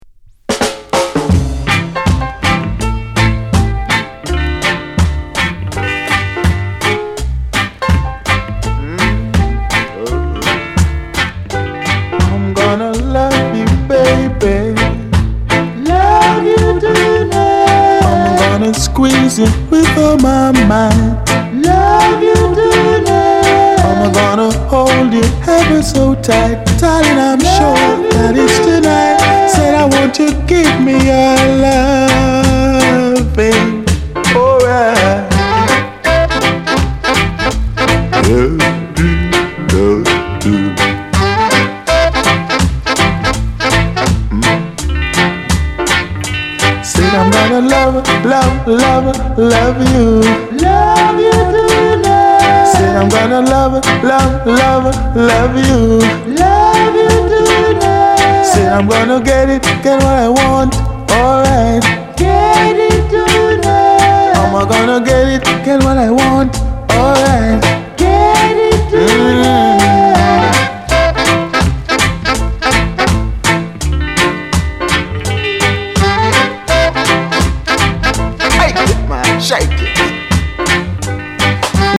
Genre:  Rocksteady